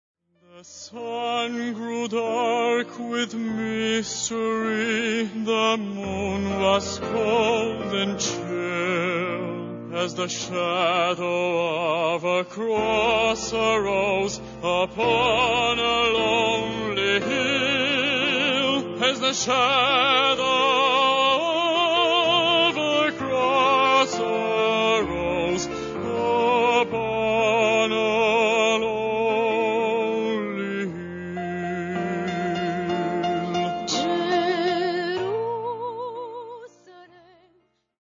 Coloratur Sopranistin
Bariton